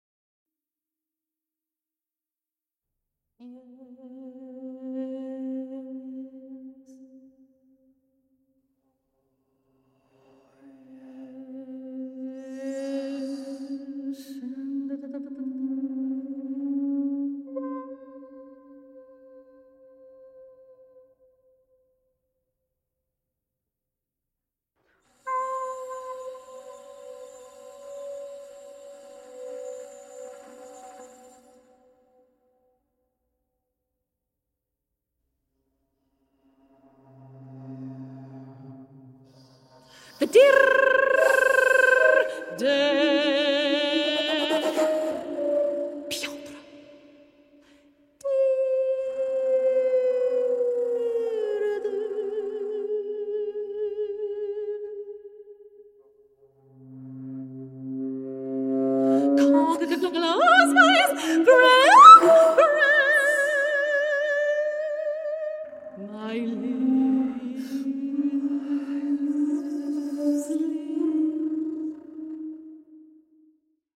soprano and flute